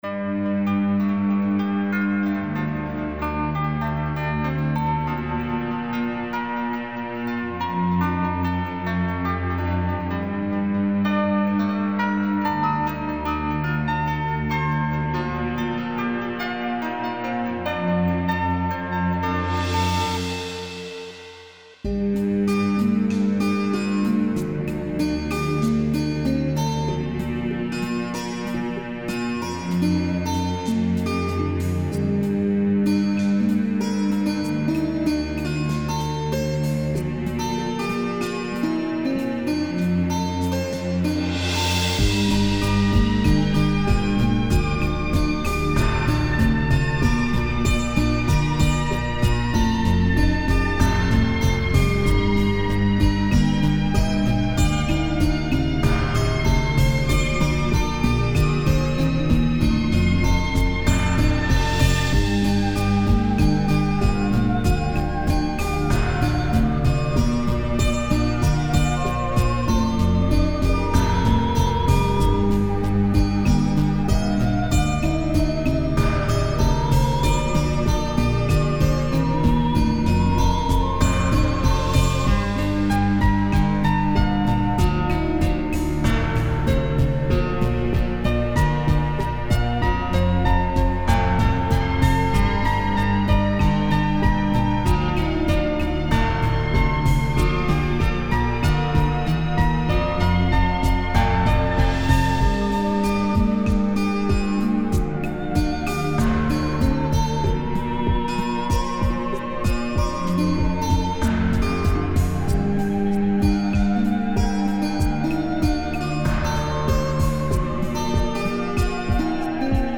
выкладываю "минус"
Подпевка перешла в вокал))) Слушаем...